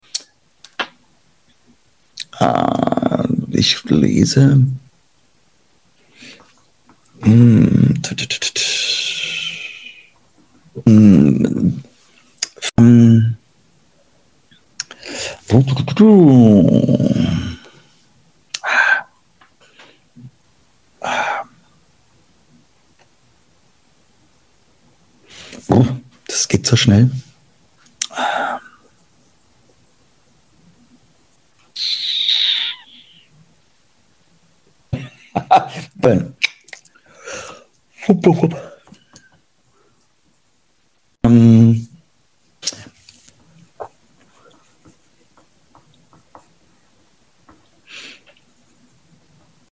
The best ASMR🤤🙏❤he is such sound effects free download
The best ASMR🤤🙏❤he is such a comfort person🥺😍💖 live stream on YouTube.